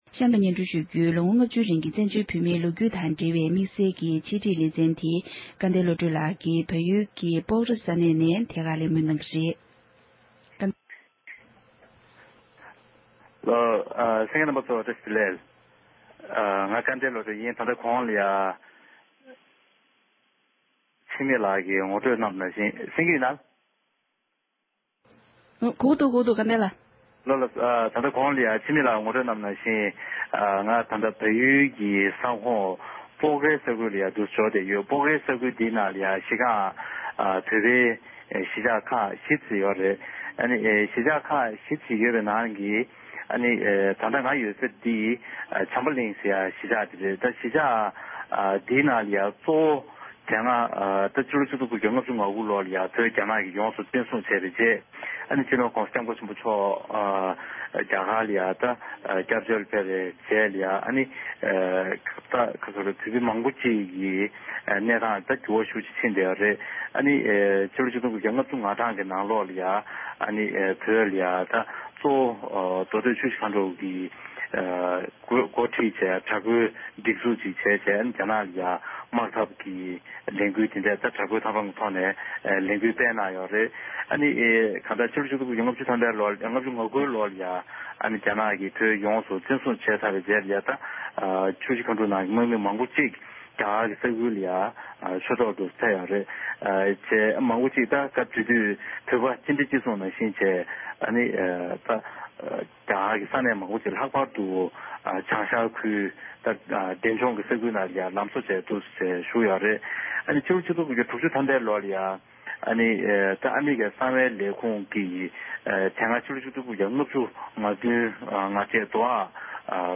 བལ་ཡུལ་ནང་བོད་རང་བཙན་ཆེད་དྲག་པོའི་དམག་འཐབ་སྤེལ་བའི་ལས་འགུལ་ནང་ཞུགས་མྱོང་མཁན་གྱི་ལྷན་དུ་གླེང་བ།